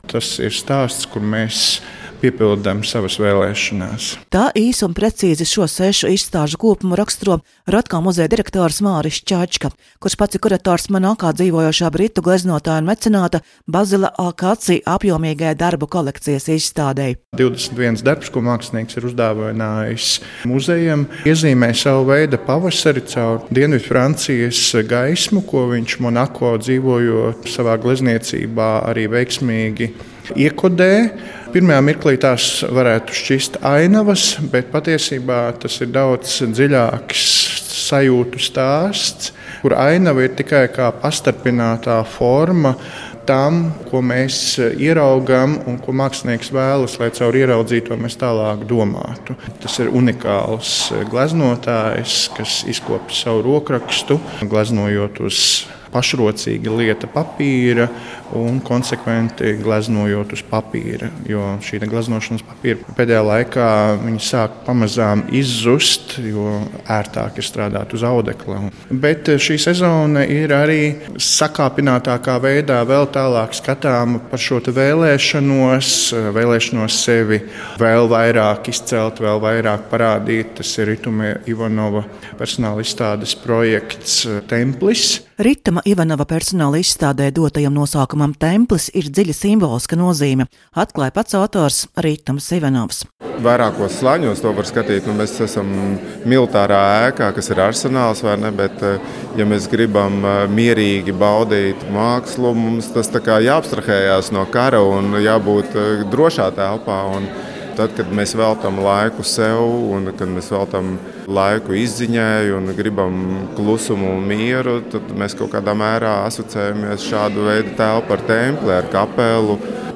Ierakstos klausāmies diskusijas fragmentus.